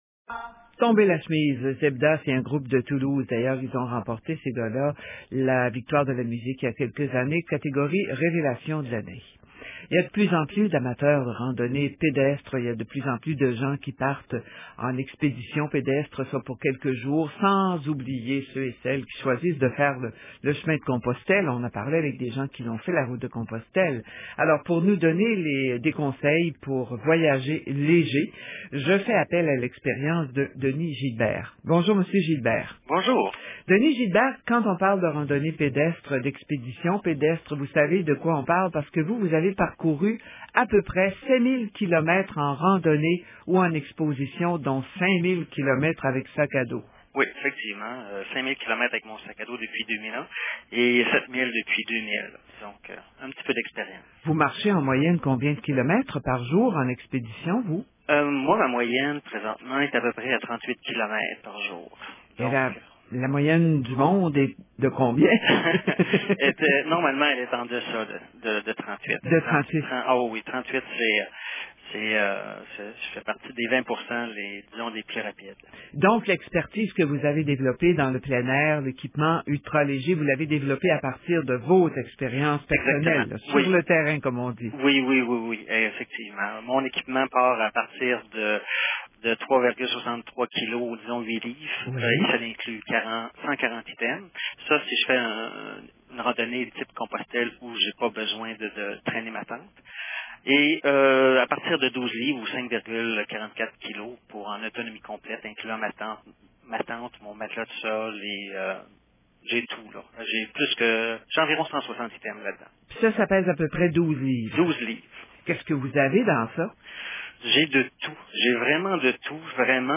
EntrevueRadioCanadaRimouski19jan05.mp3